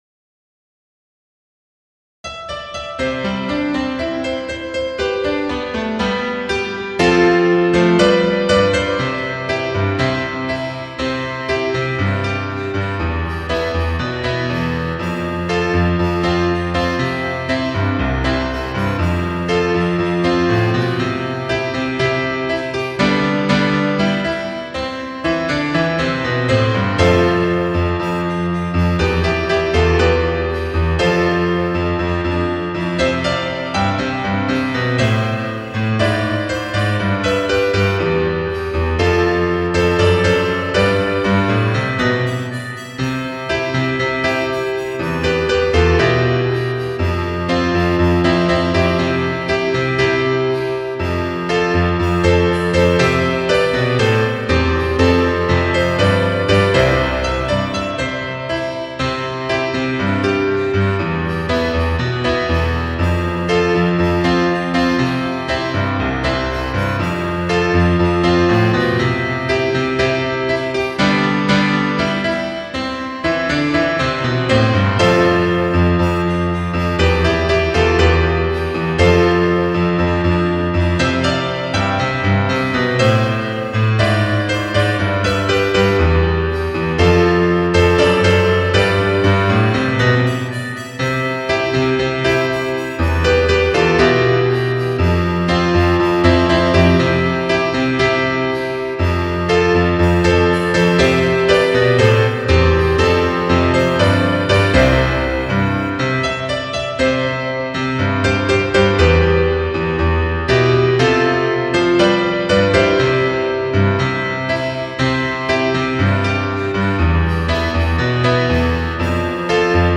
・曲調　　厳かな場や町旗掲揚時の斉唱等に適した曲調
伴奏のみ　フルコーラス　音楽データ（ファイル：3.25メガバイト） 別ウィンドウで開きます